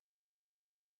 silent.opus